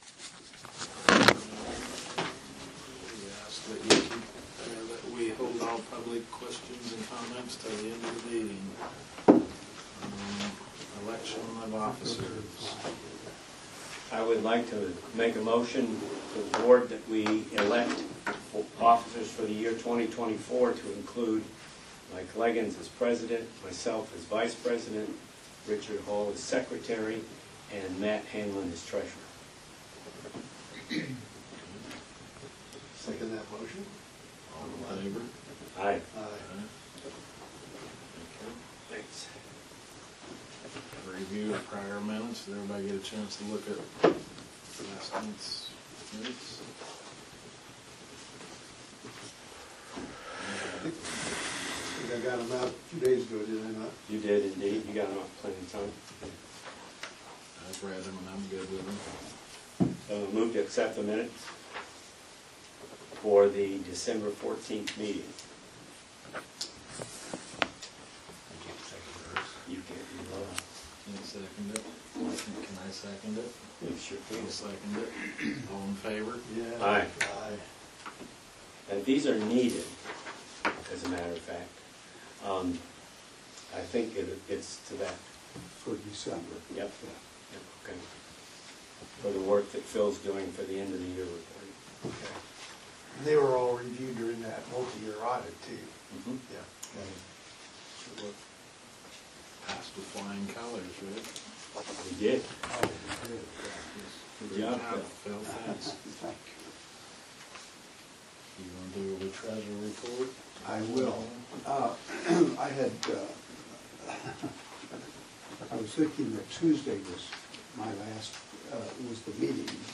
Audio of the Meeting.